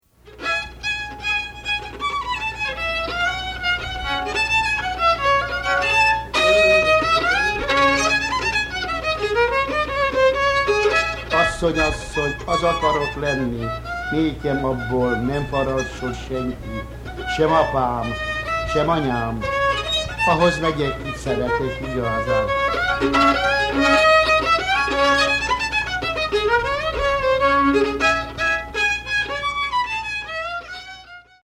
Hungarian Folk Music in the United States
Songs on Violin
The excerpt heard here is a characteristic combination of dance melodies into a csárdás comprised of a medium temp and a fast variation.